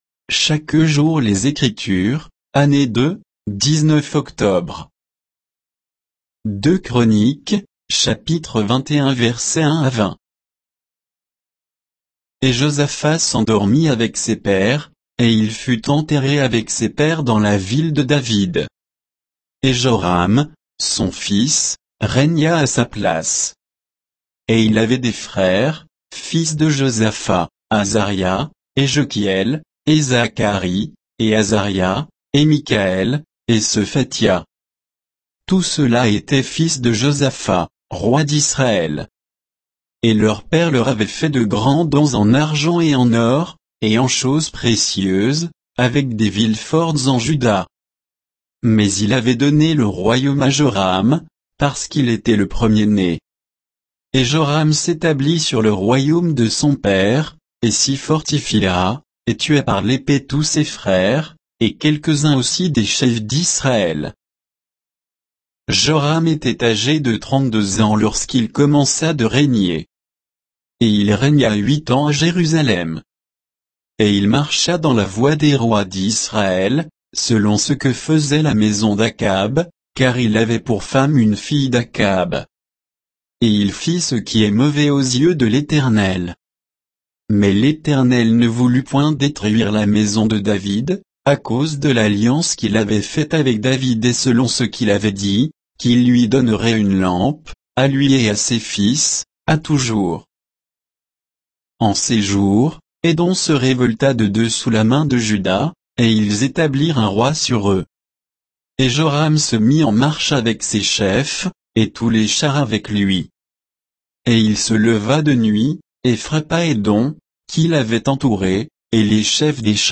Méditation quoditienne de Chaque jour les Écritures sur 2 Chroniques 21, 1 à 20